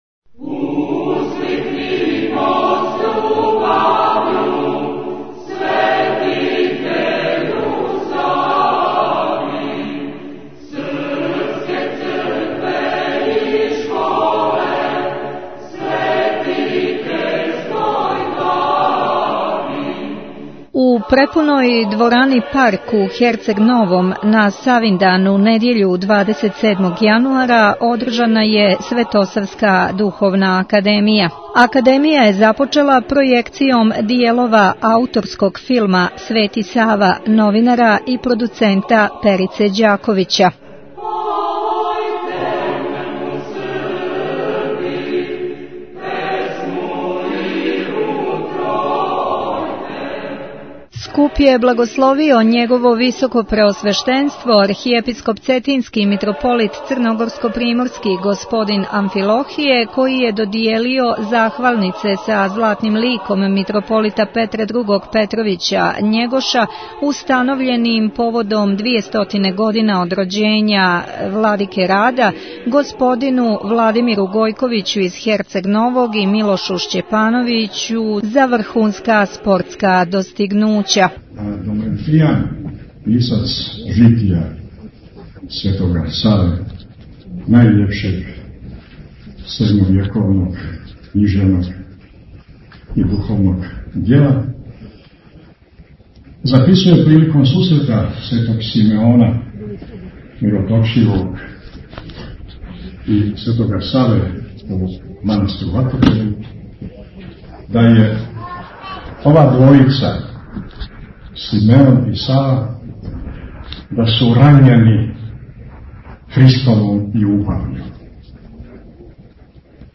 У препуној херцегновској дворани "Парк" одржана је свечана академија посвећена Светом Сави
Извјештаји